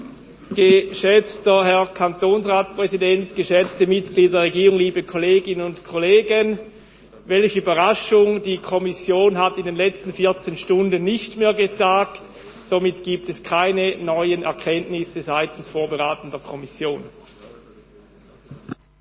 2.12.2020Wortmeldung
Session des Kantonsrates vom 30. November bis 2. Dezember 2020